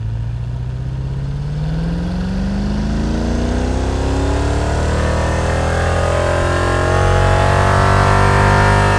rr3-assets/files/.depot/audio/Vehicles/v8_12/v8_12_accel.wav
v8_12_accel.wav